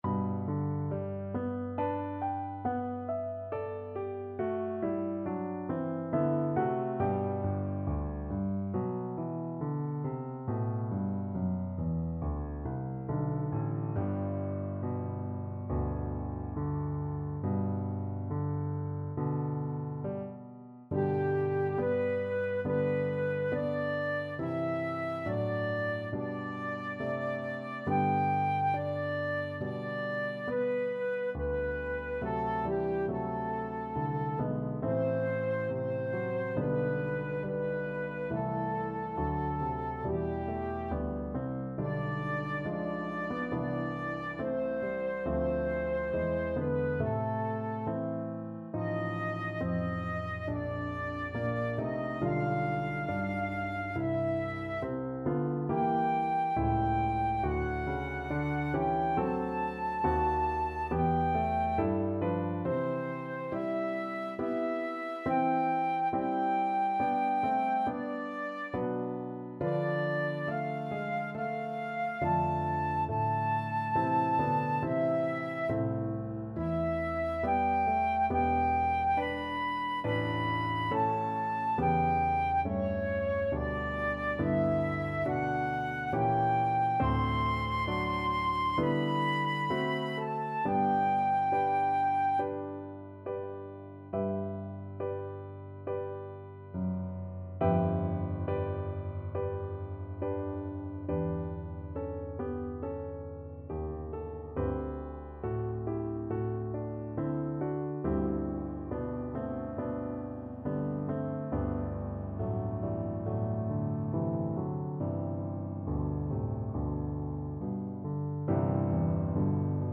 Flute
2/2 (View more 2/2 Music)
Slow =c.69
G major (Sounding Pitch) (View more G major Music for Flute )
Classical (View more Classical Flute Music)